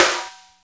45.03 SNR.wav